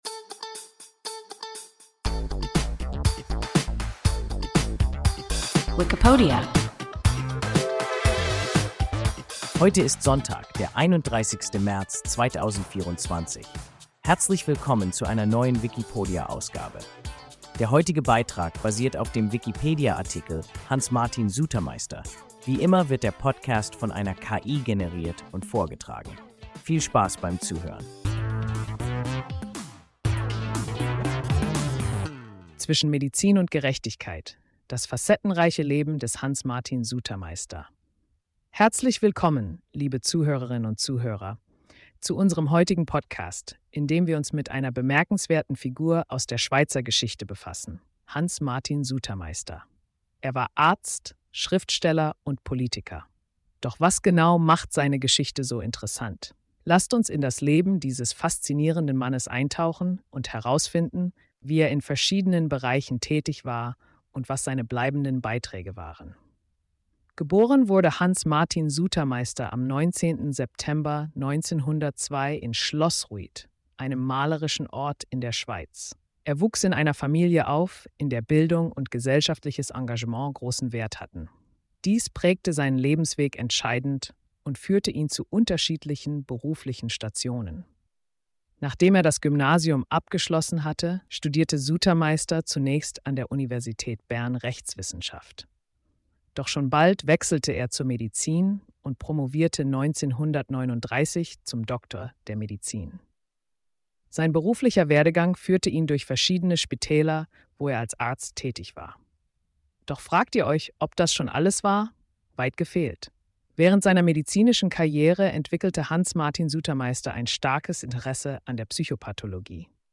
Hans Martin Sutermeister – WIKIPODIA – ein KI Podcast